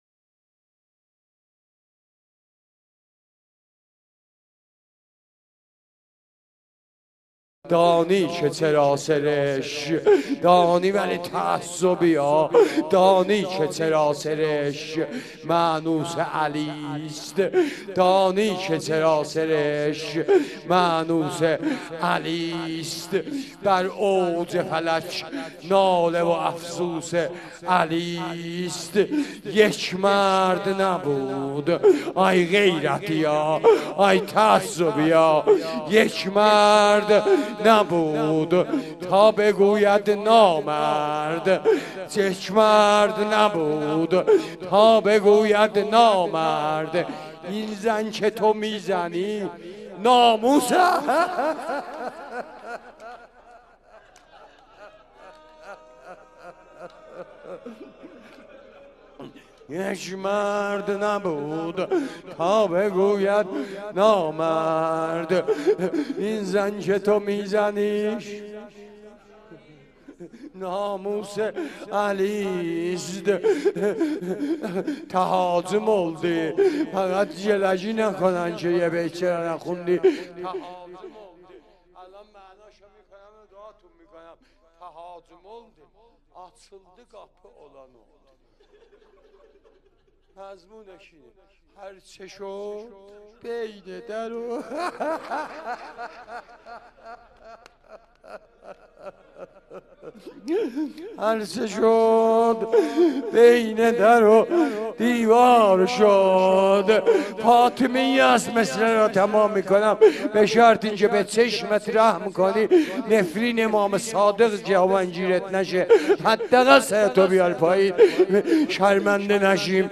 روضه و مداحی | دانی که چرا سرشک مانوس علیست ...
روز اول فاطمیه اول - اسفند ماه سال 1394